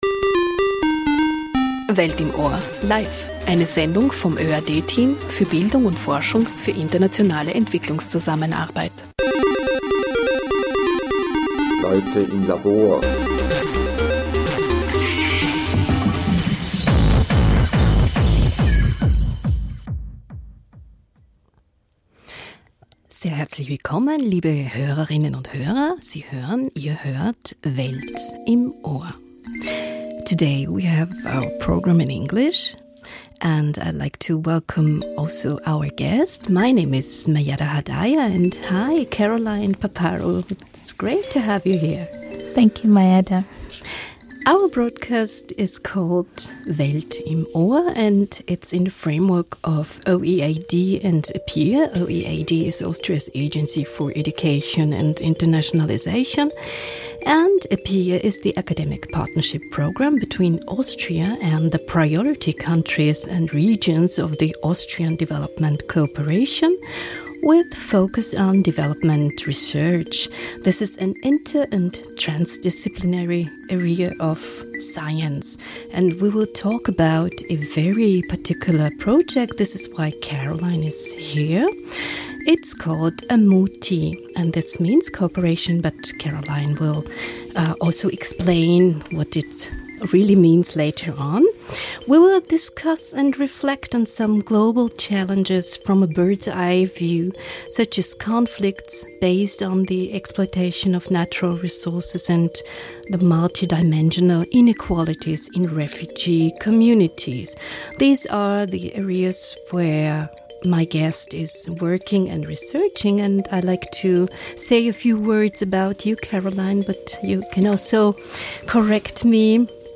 Weihnachtssendung live aus dem ORF Zentrum